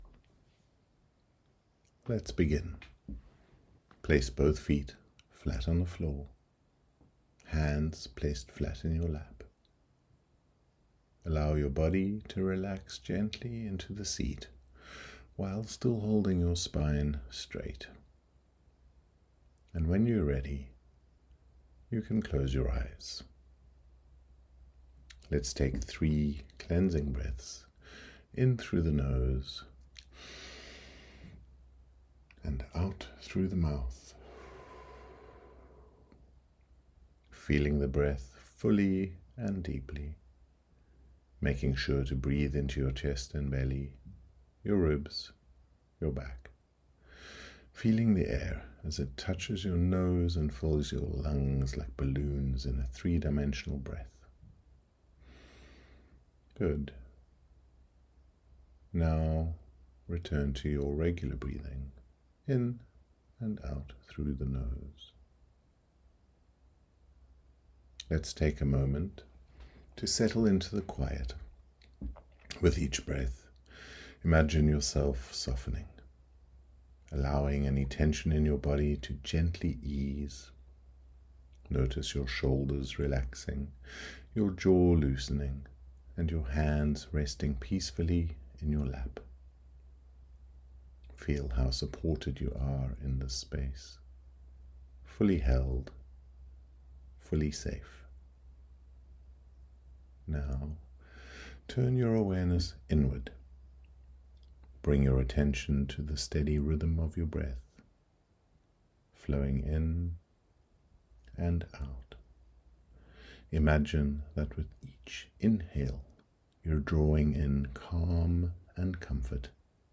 Workshop Meditation
MB03-meditation.mp3